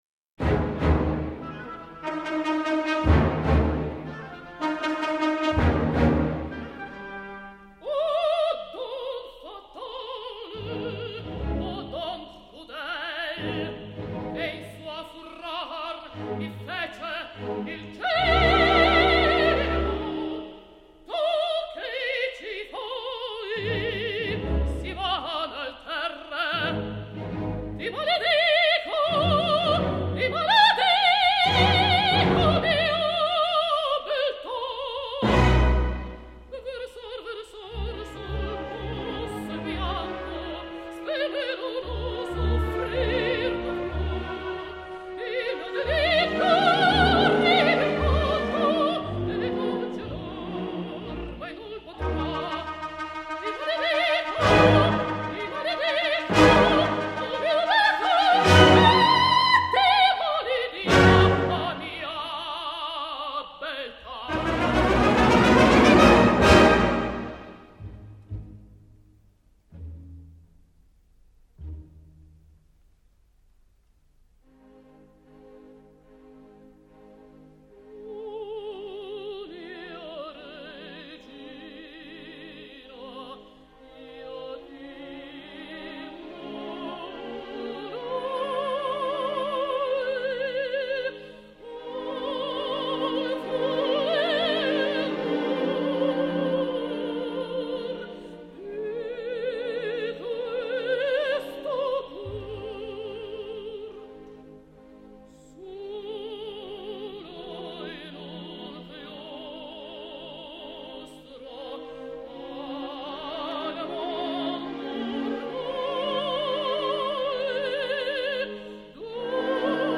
Исп.Е.Образцова